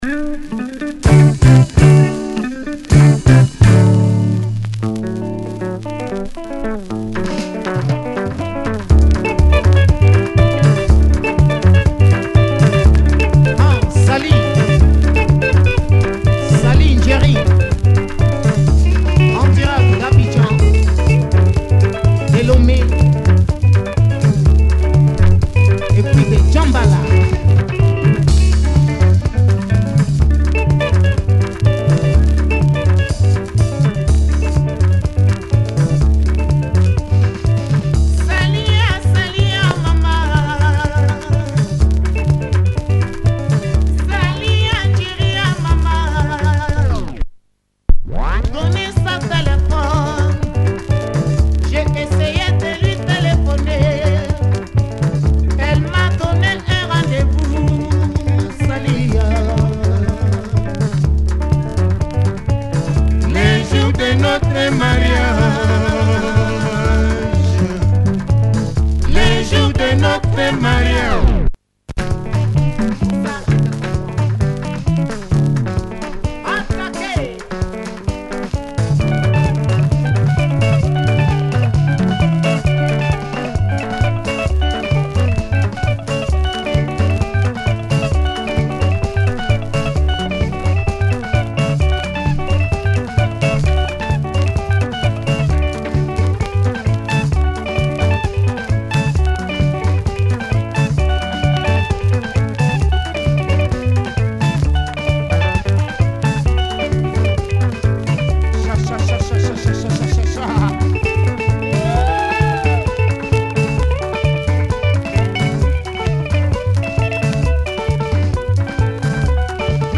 Nice Lingala